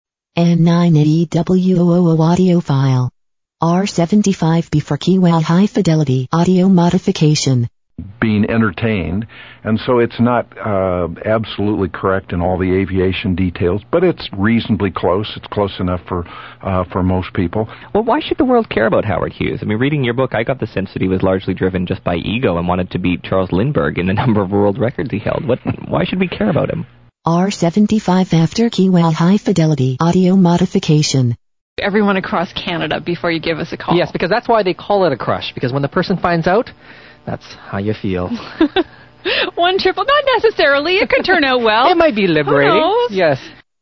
It really helps reduce the IC-R75’s audio to contain excessive bass or muffled sounding.
Icom IC-R75 BEFORE and AFTER Kiwa Electronics "Audio Upgrade -"Hi-Fi Audio Upgrade" Modifications.
Using same "Radio Canada International" transmitter/frequency/time. Wide AM bandwidth (12~15 kHz) / identical settings.